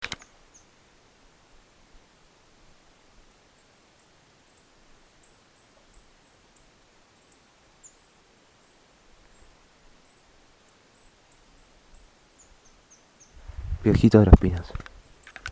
Picaflor Vientre Blanco (Elliotomyia chionogaster)
Nombre en inglés: White-bellied Hummingbird
Localidad o área protegida: Parque Nacional Calilegua
Condición: Silvestre
Certeza: Fotografiada, Vocalización Grabada
Picaflor-Vientre-Blanco.MP3